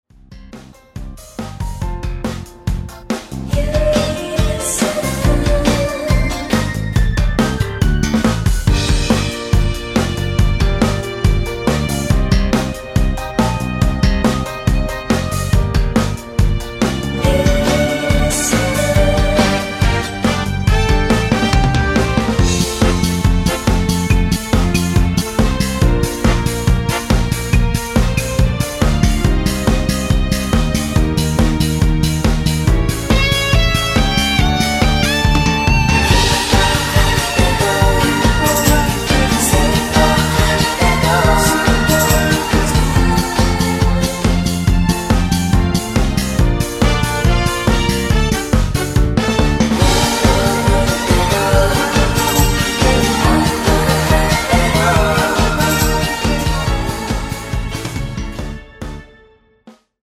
코러스 포함된 MR 입니다 (미리듣기 참조 하세요!)
Gm
앞부분30초, 뒷부분30초씩 편집해서 올려 드리고 있습니다.